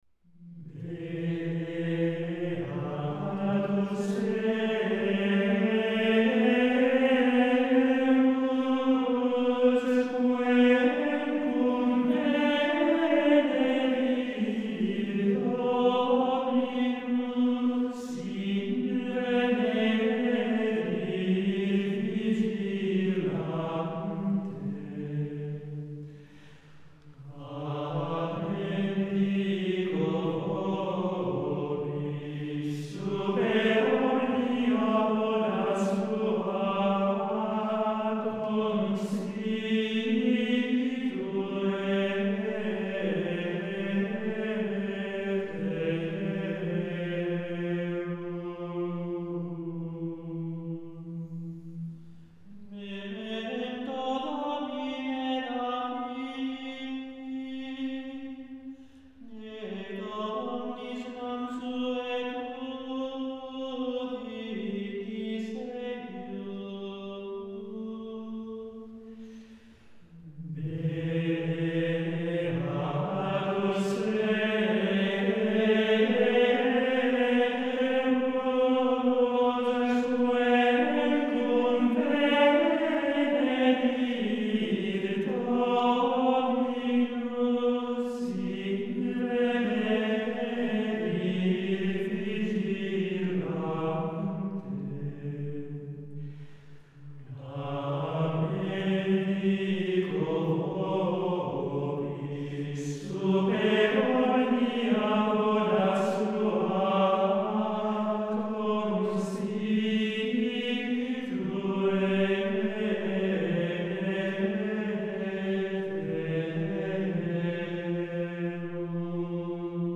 copia de cantoral gregoriano , Alonso de Villafañe (escritor de libros) , Andrés Gutiérrez (pergaminero) , copista , pergaminero , Bernardino Rodríguez (canónigo, provisor del arzobispado)
Beatus servus. Comunión. Modo III. Intérpretes: Schola Antiqua.